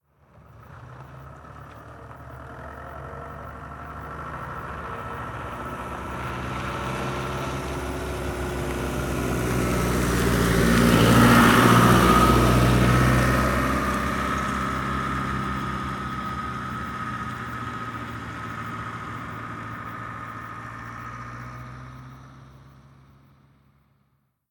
Furgoneta pasando
furgoneta
Sonidos: Transportes